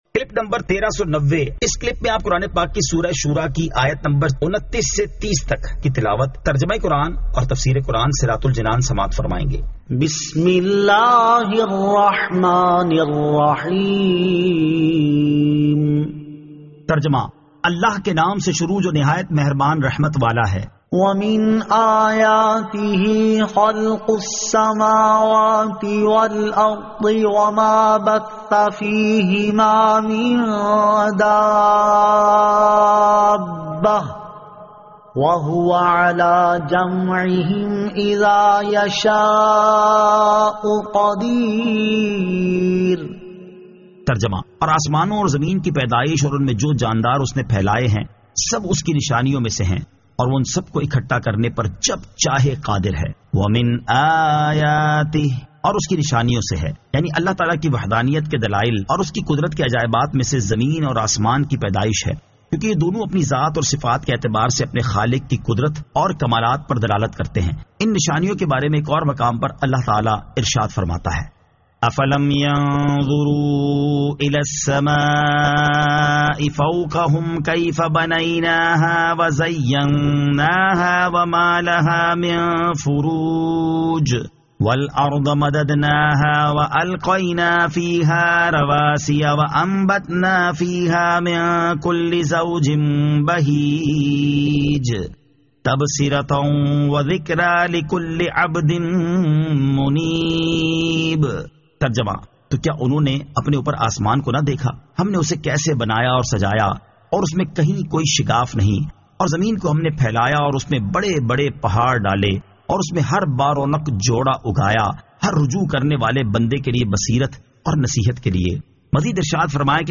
Surah Ash-Shuraa 29 To 30 Tilawat , Tarjama , Tafseer